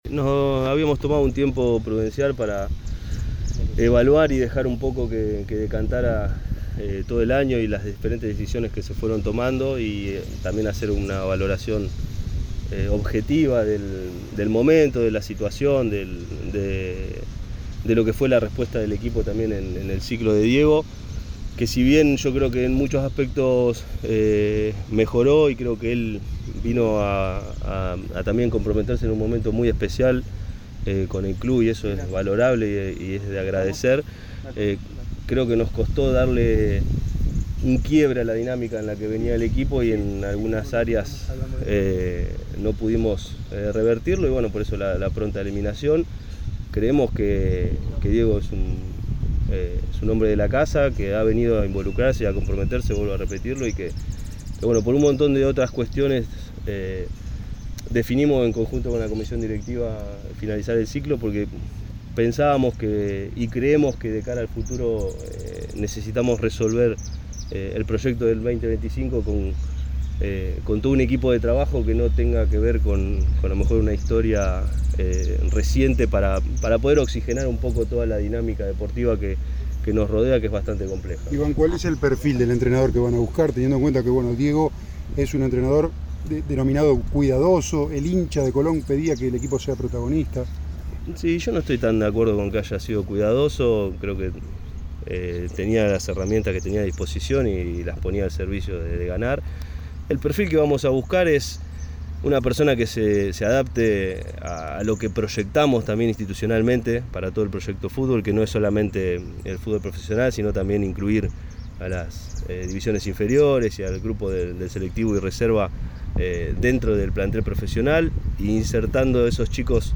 brindó una conferencia de prensa donde brindó algunos conceptos del proyecto para el 2025.